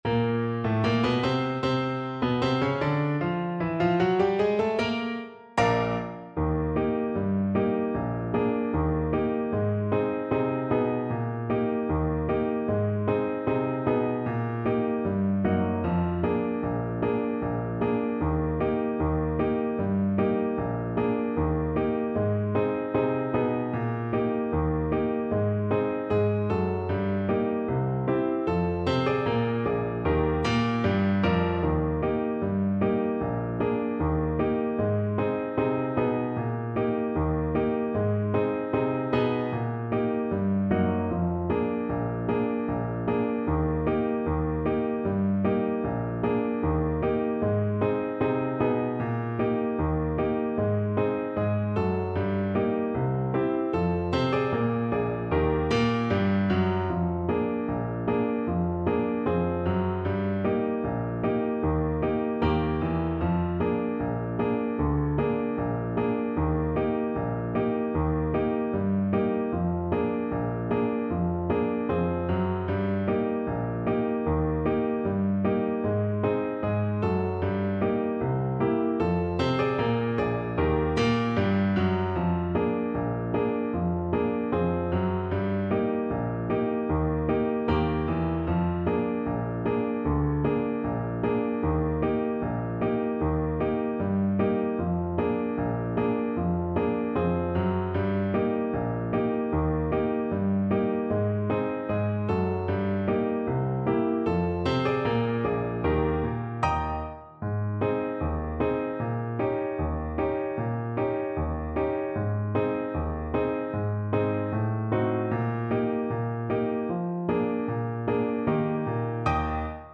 Play (or use space bar on your keyboard) Pause Music Playalong - Piano Accompaniment Playalong Band Accompaniment not yet available transpose reset tempo print settings full screen
Trombone
2/4 (View more 2/4 Music)
Eb major (Sounding Pitch) (View more Eb major Music for Trombone )
Tempo di Marcia = 76 Tempo di Marcia
Ragtime Music for Trombone